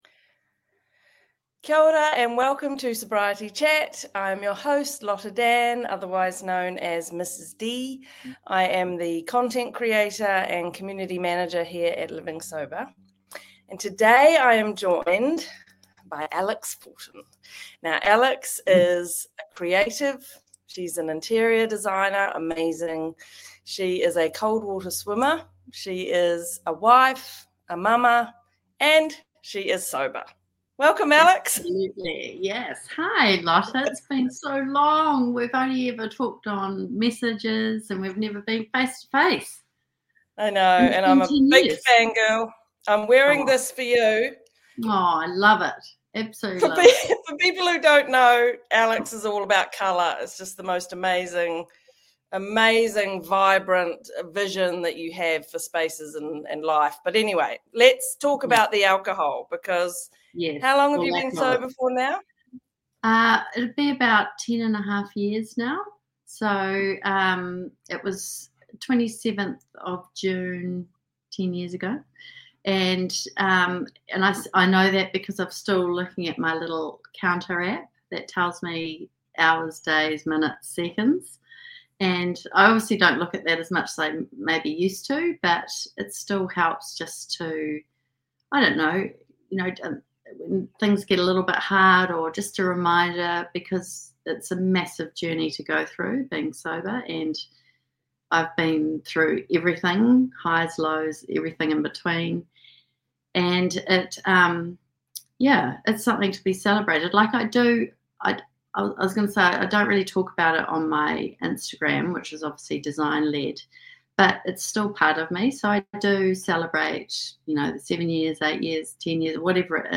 January 20th, 2025 Interviews 2 comments